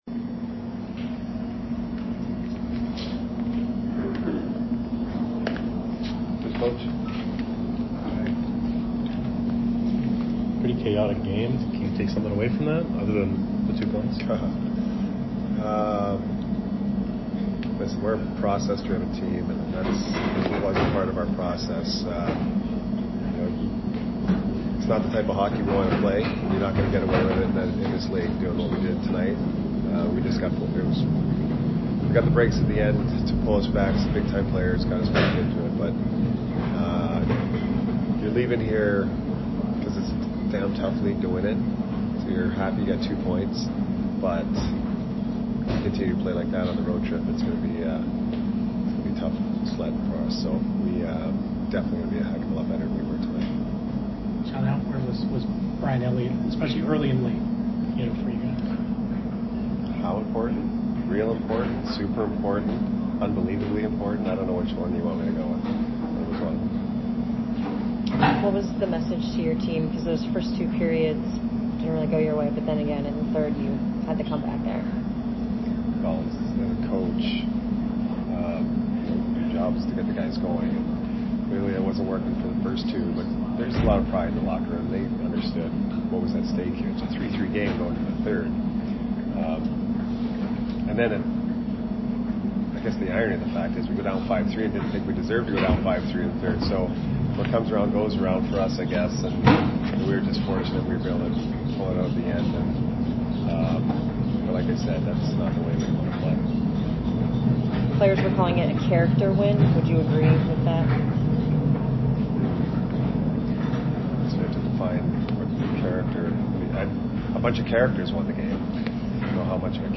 Head Coach Jon Cooper Post Game 11/28/22 at BUF